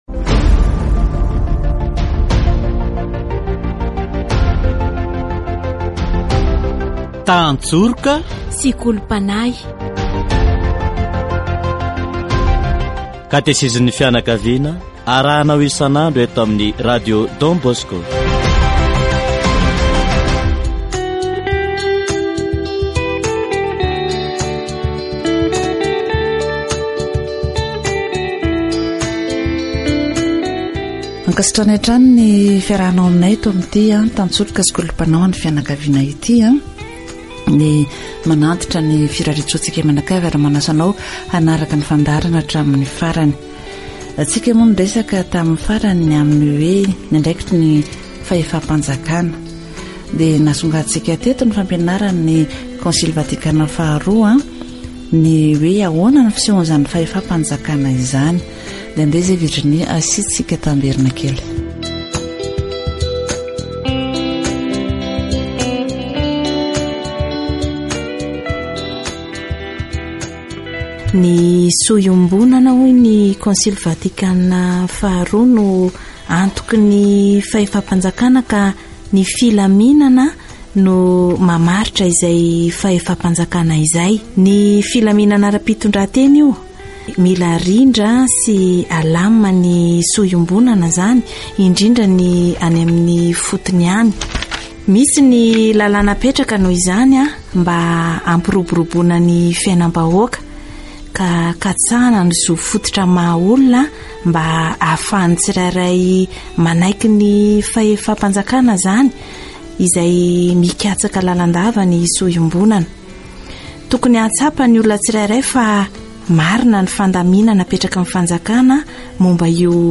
Si les dirigeants ne s'assoient pas et ne respectent pas les postes et les responsabilités qu'ils devraient assumer, c'est-à-dire qu'ils outrepassent leur autorité et oppriment les citoyens, alors les citoyens ne peuvent pas refuser de faire ce qui est essentiel pour le bien commun. c'est-à-dire ce qu'il considère comme bon pour poursuivre le bien public. Catéchèse sur la famille